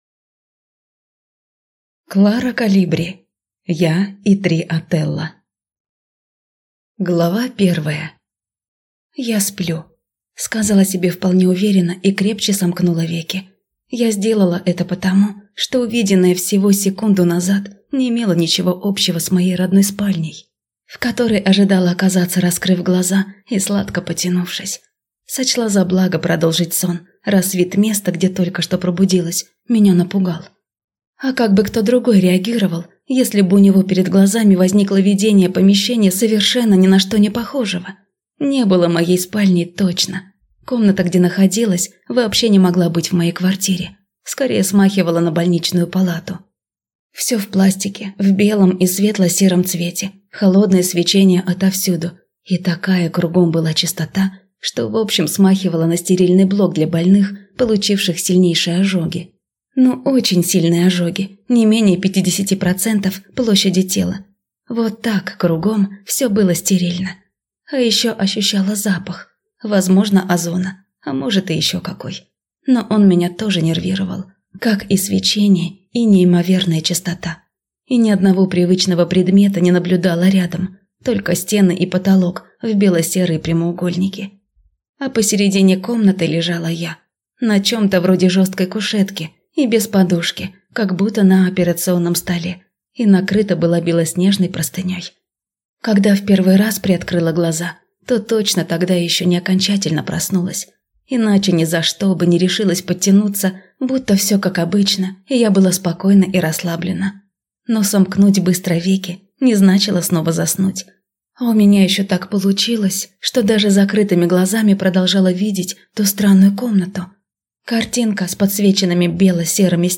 Аудиокнига Я и три Отелло | Библиотека аудиокниг
Прослушать и бесплатно скачать фрагмент аудиокниги